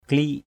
/kli:ʔ/ 1. (t.) nhỏ mạt, nhỏ mịn = en poussière. klik yau tapung k*{K y~@ tp~/ nhỏ mịn như bột. 2.
klik.mp3